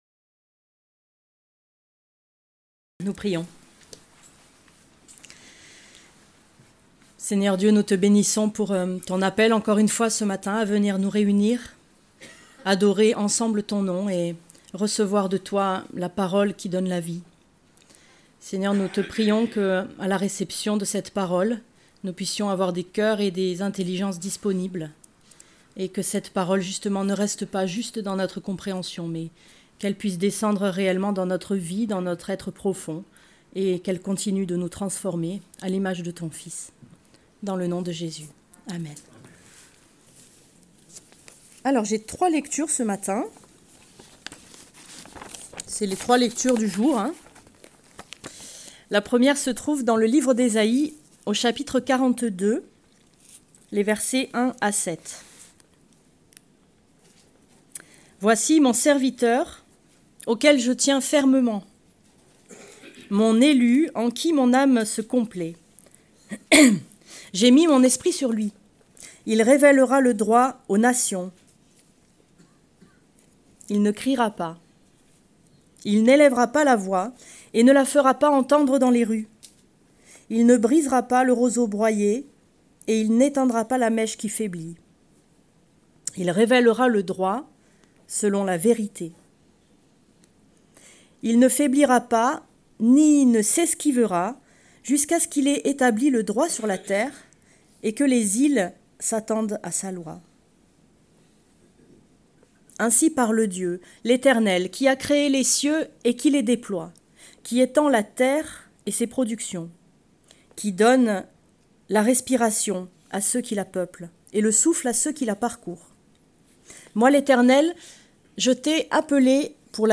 Prédication du 11 décembre 2026.